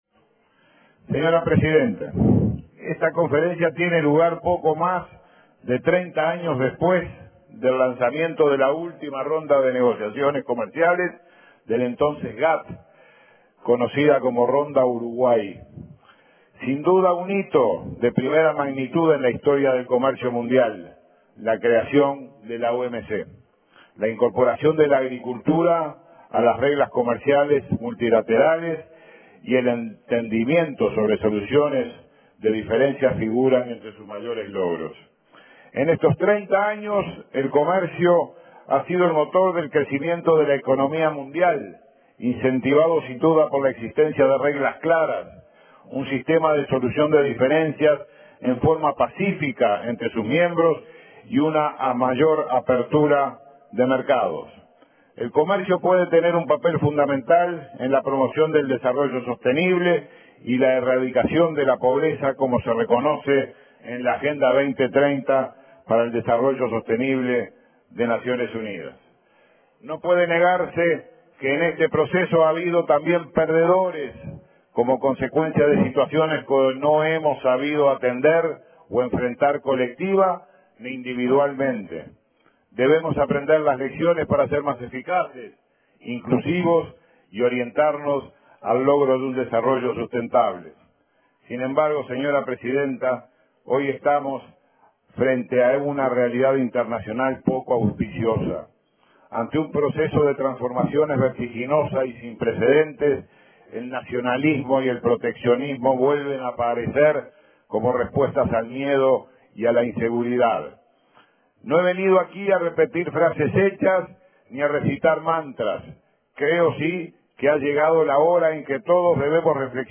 “Uruguay reafirma su profunda vocación multilateralista y su compromiso con el sistema multilateral de comercio basado en reglas”, reafirmó el canciller Nin Novoa en la primera sesión plenaria de la Organización Mundial de Comercio. Habló del acuerdo sobre agricultura, dijo que Uruguay puede contribuir a resolver los problemas de seguridad alimentaria mundial y abogó por un plan que encare una negociación seria y equitativa.